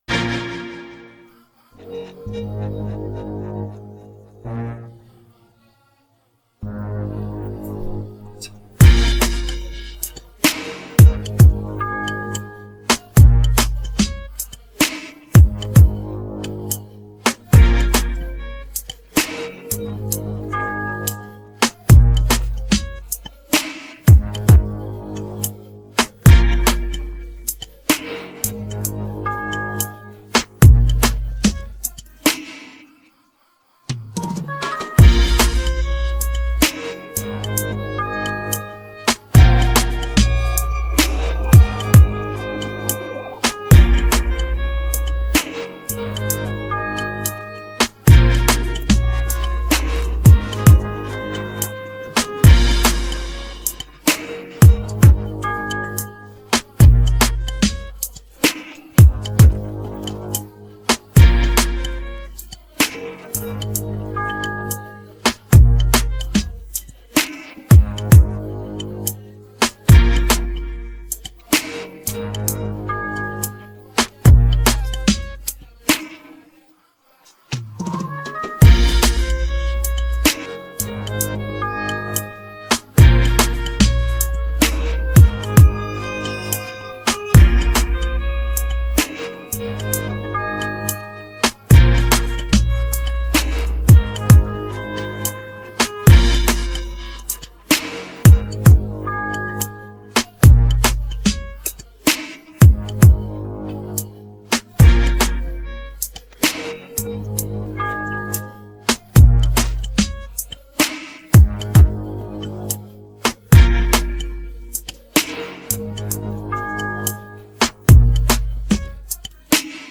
DancehallTrending